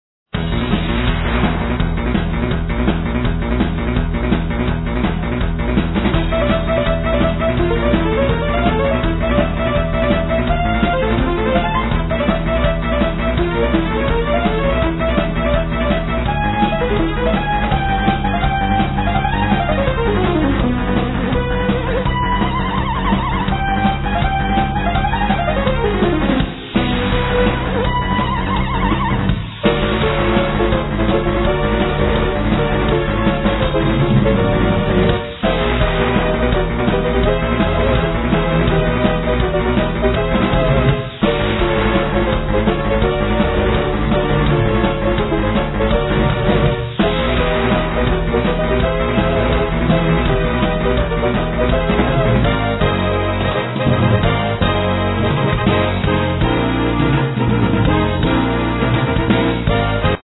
Keyboards, Vocals
Drums, Percussions, Bass guitar, Vocals
Flute
Cello
Violin
Trumpet
Guitar